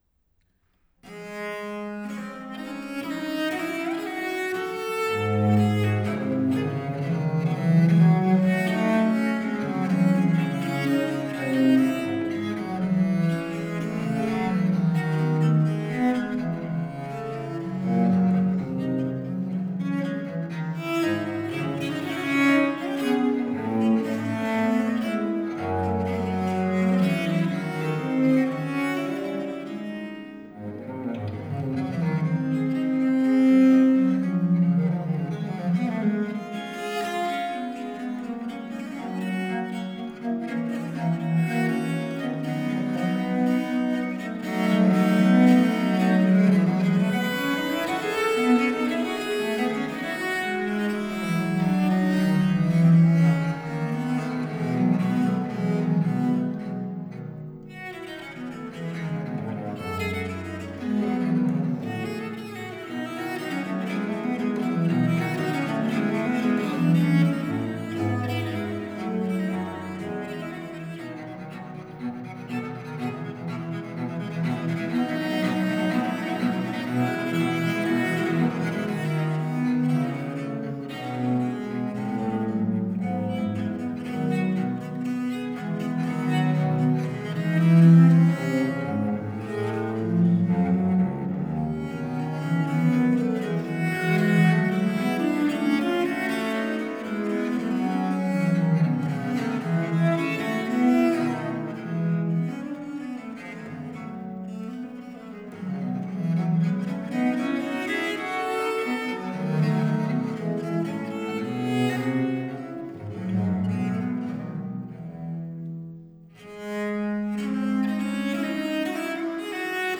Duett für zwei Viole di Gambe - Poco Allegro
07-Duetto-for-2-Viola-da-Gamba.flac